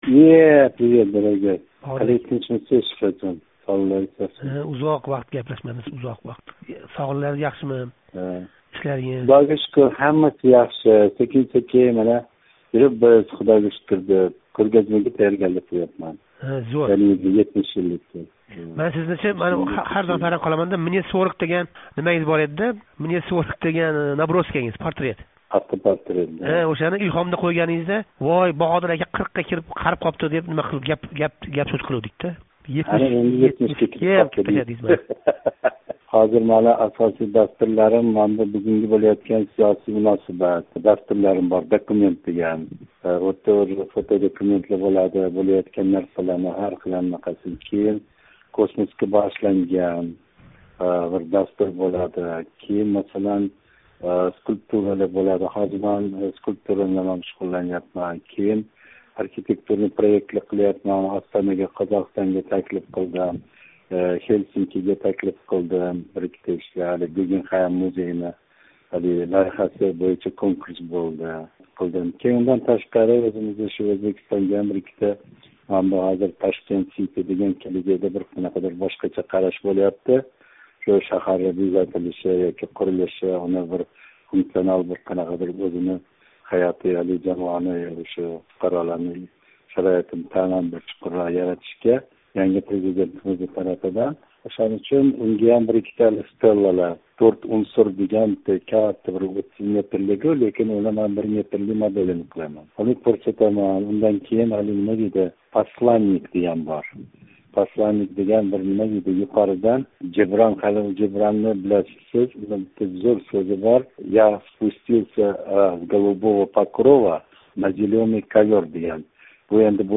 Рассом Баҳодир Жалол билан суҳбат